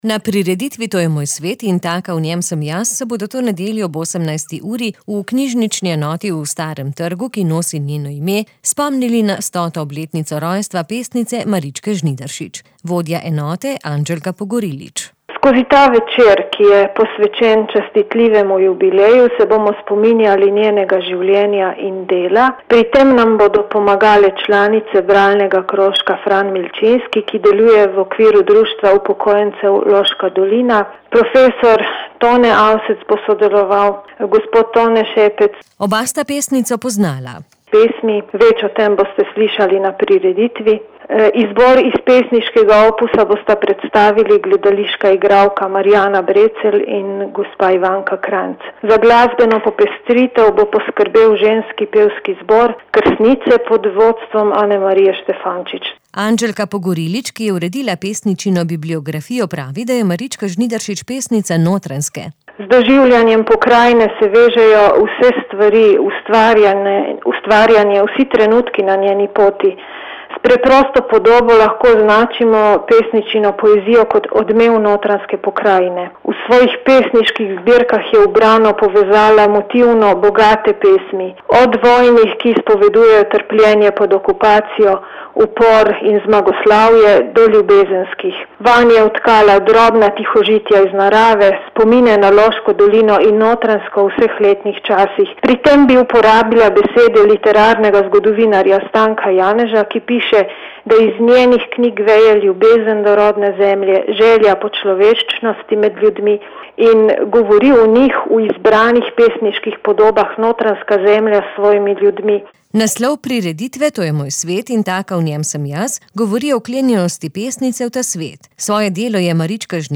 Na Baču in v Knežaku so se razmere umirile, je za Radio 94 povedal župan Emil Rojc.